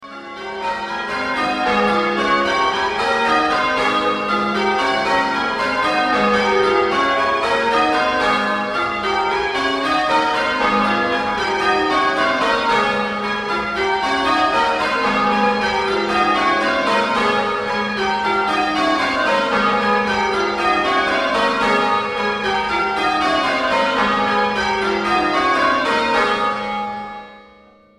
All agreed that the bells sounded absolutely superb, the Whitechapel Mklll profiles producing a very impressive sound.The bells ring very easily and after installing a layer of carpet on the clock chamber floor, the internal acoustics are excellent.
St Peter and St Paul Date: 2011, Stedman Triples.
Tenor 9cwt 15Ibs in G#
shiplake_bells_stedman_triples.mp3